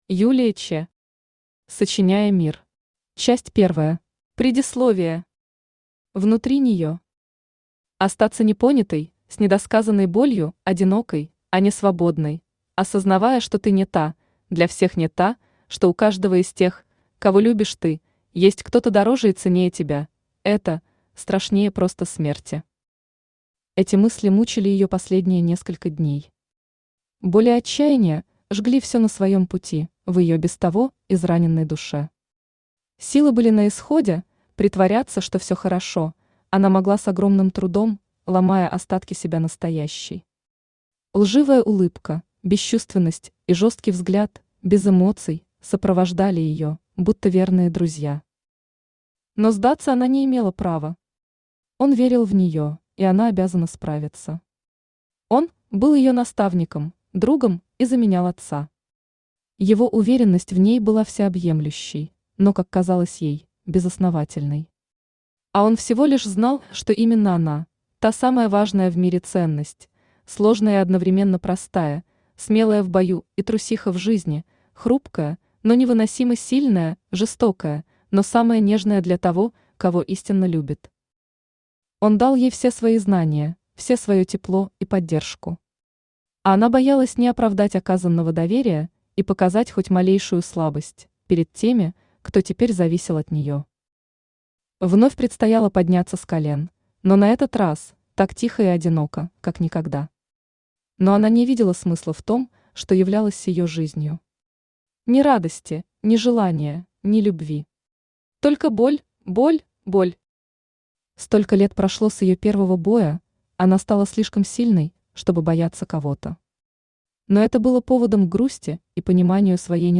Аудиокнига Сочиняя мир. Часть первая | Библиотека аудиокниг
Часть первая Автор Юлия Che Читает аудиокнигу Авточтец ЛитРес.